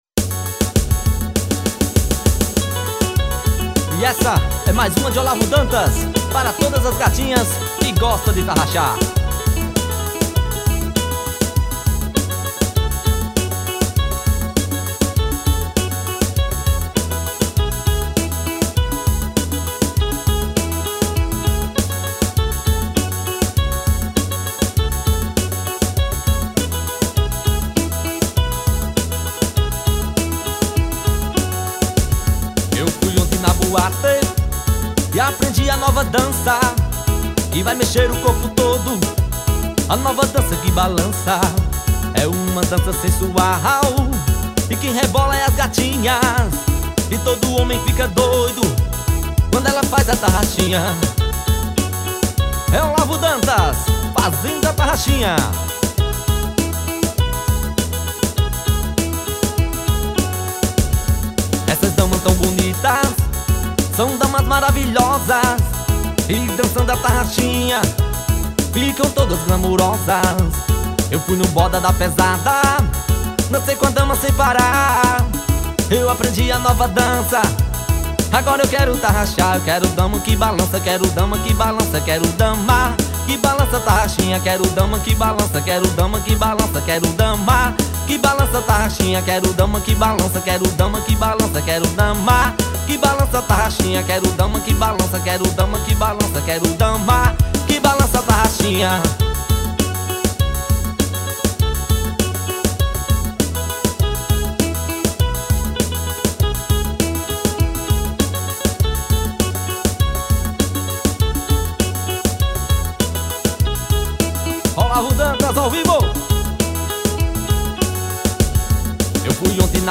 tarraxinha.